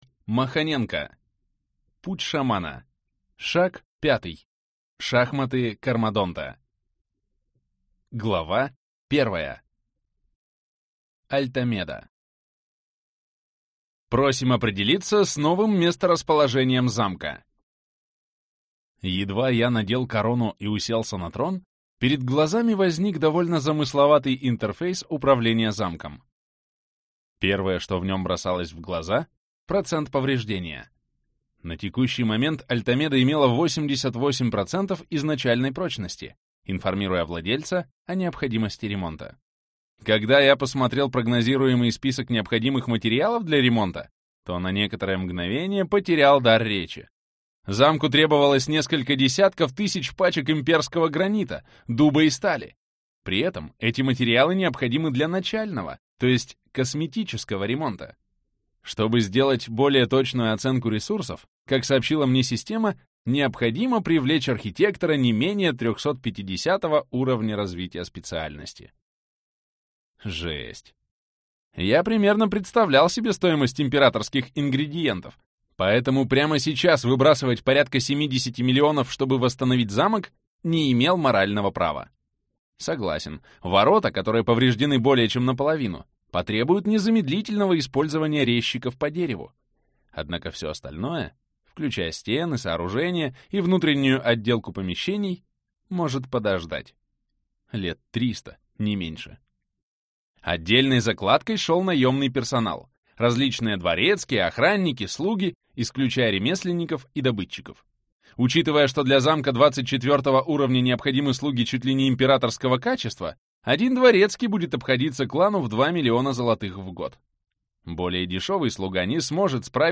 Аудиокнига Путь Шамана. Шаг 5. Шахматы Кармадонта | Библиотека аудиокниг